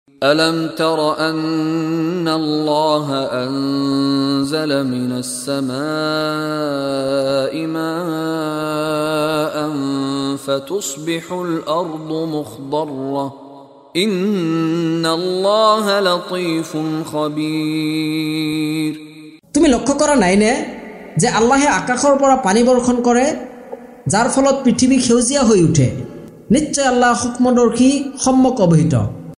লগতে ক্বাৰী মিশ্বাৰী ৰাশ্বিদ আল-আফাছীৰ কণ্ঠত তিলাৱত।